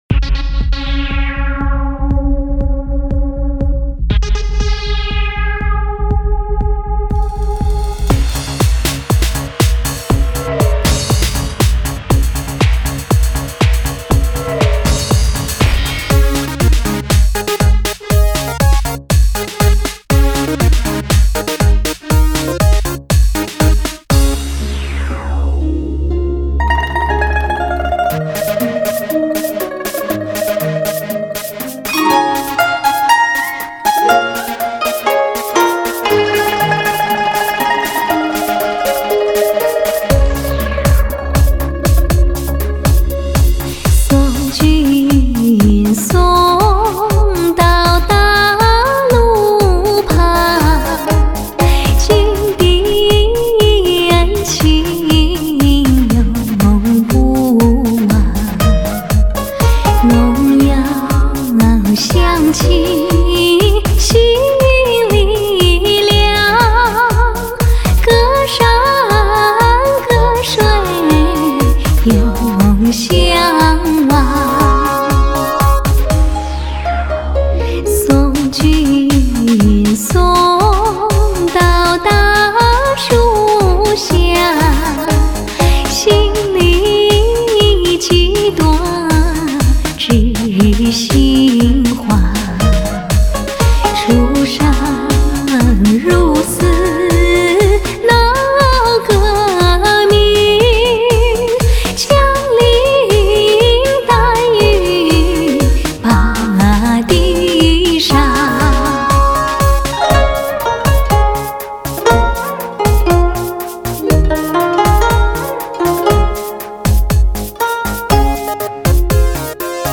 真是不一样的摇滚发烧新音乐，谢谢！